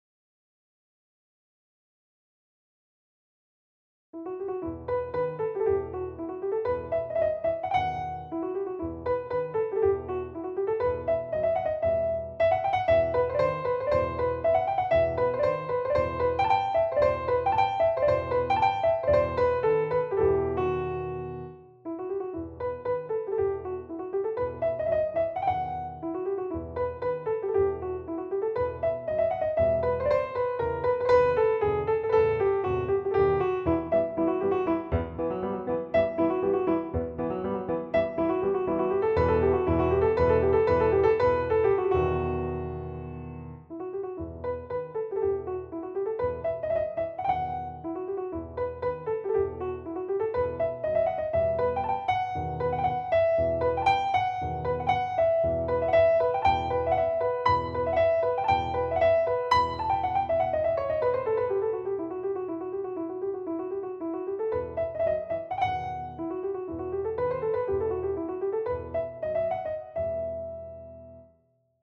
一方、比較するためのもう一つのファイルは、Pro Toolsプラグイン「Mini Grand」のアコースティックピアノ音源を鳴らして、同じ24bit/48kHzでバウンスしたもの。
２．Pro Toolsプラグイン「Mini Grand」のアコースティックピアノ音源をMIDIデータによって鳴らし、「タンブラン」の演奏をレコーディング。
リバーブは「Mini Grand」内のエフェクトを使用。
音圧上げ無し。